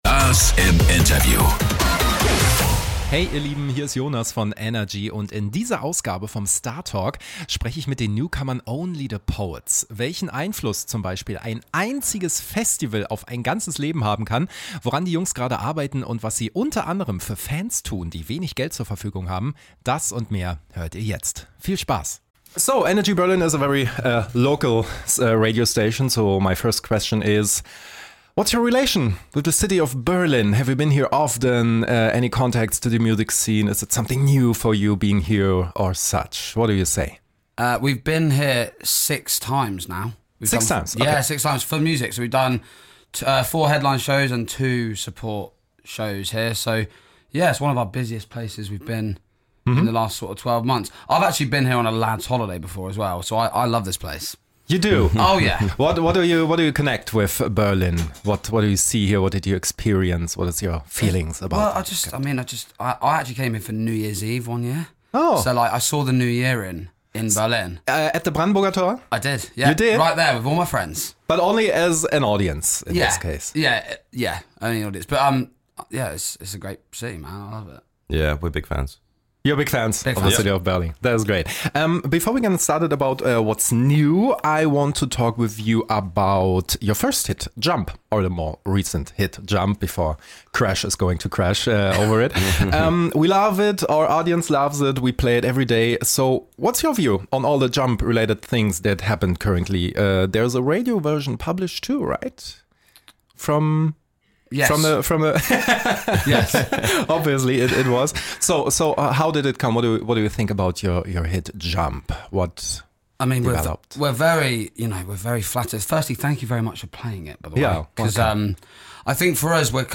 Oder es liegt an dem starken britischen Akzent. Außerdem erfahrt ihr, wie die Band zu ihrem Namen kam und was ihr größter Traum ist.